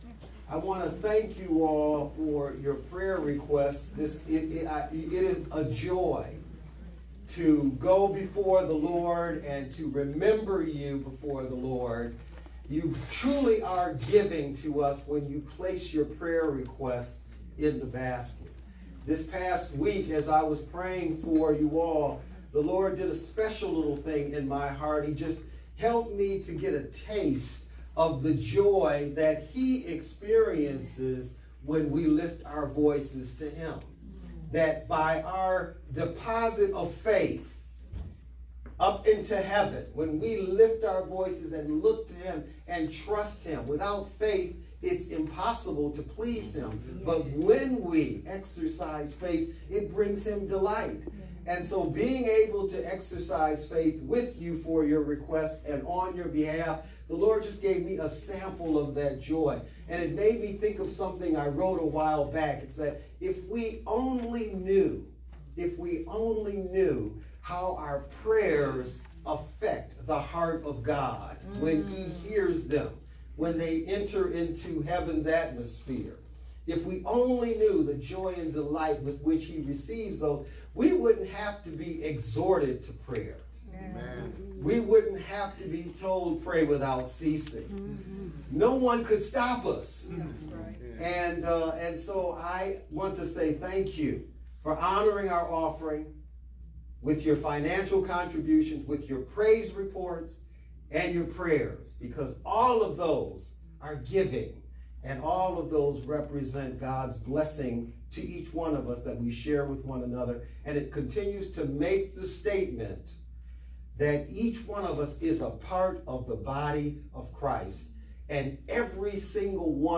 VBCC-Sermon-only-3-10_Converted-CD.mp3